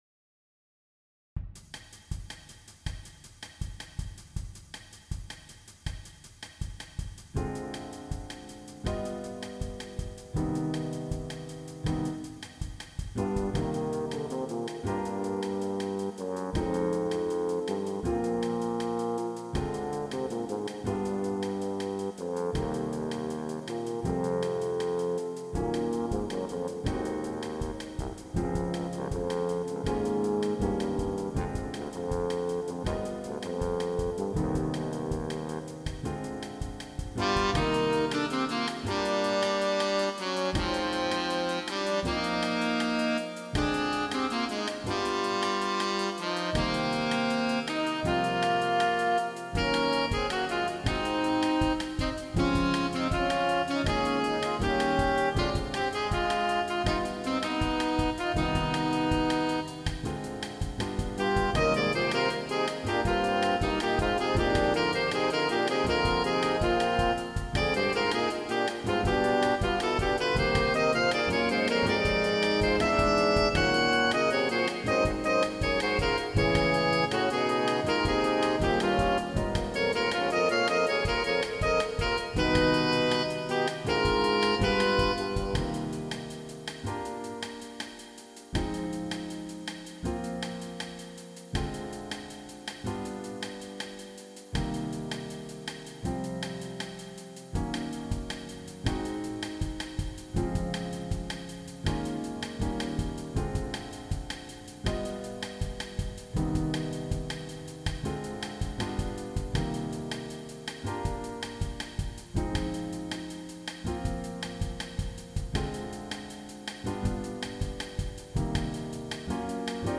All audio files are computer-generated.